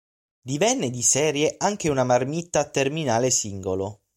mar‧mìt‧ta
/marˈmit.ta/